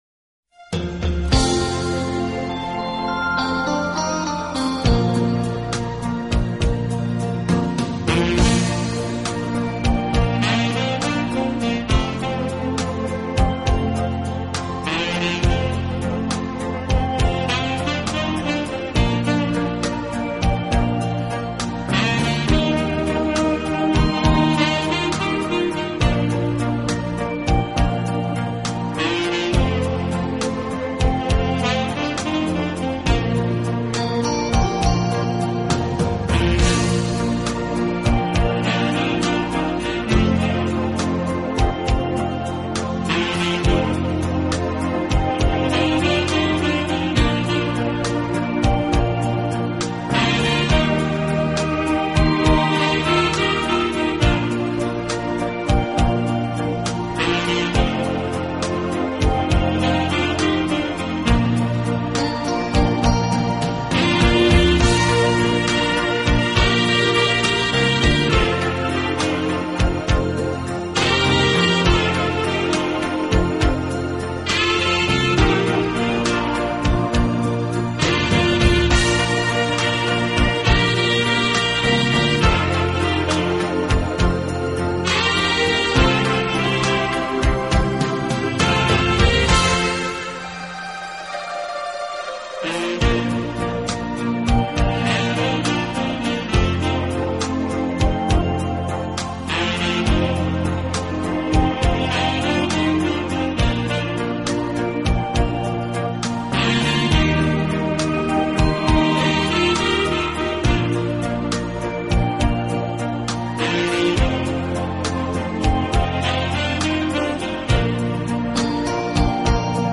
的轻音乐团，以萨克斯管为主，曲目多为欢快的舞曲及流行歌曲改编曲。
轻快、柔和、优美，带有浓郁的爵士风味。